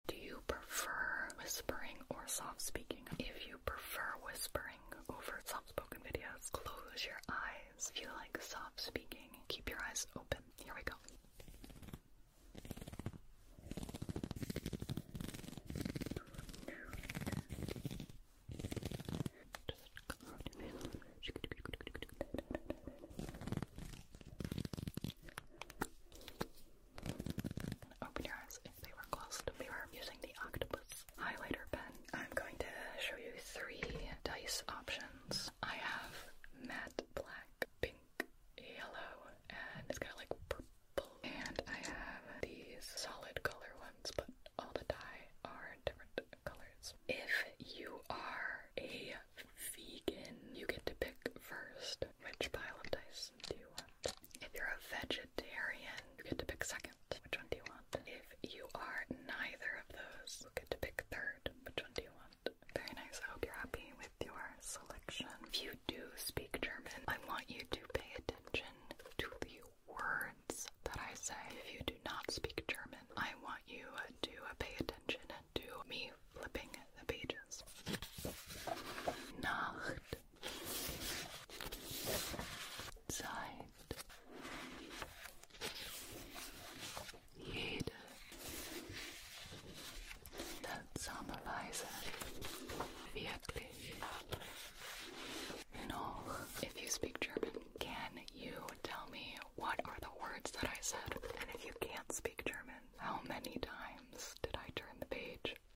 Let's do some Follow the Instructions ASMR, but the instructions are personalized to YOU!